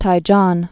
(tī-jôn)